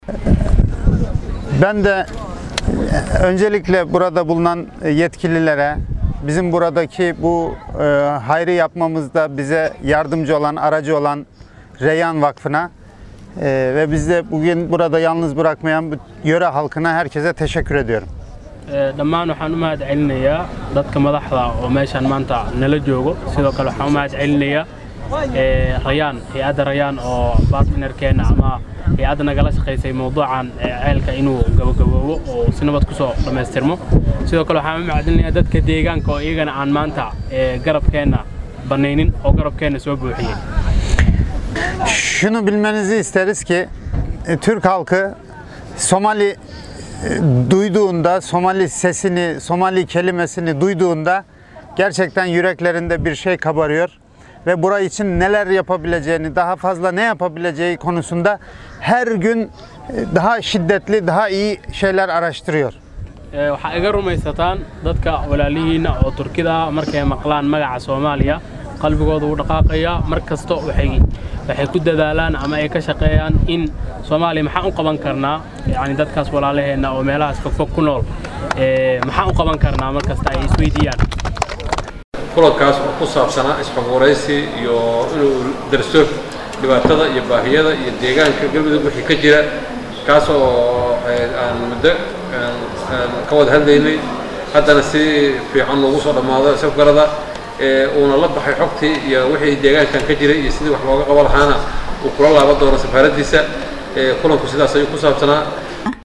Guddoomiayah Magaalada Cadaado C/laahi C/raxmaan Tootoole iyo Xubnihii ka socday Turkiga ayaa si wada jira ola hadlay Warbaahinta Qaranka.